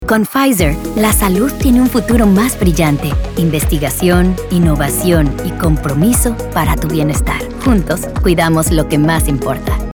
Commercial
Calm - Reassuring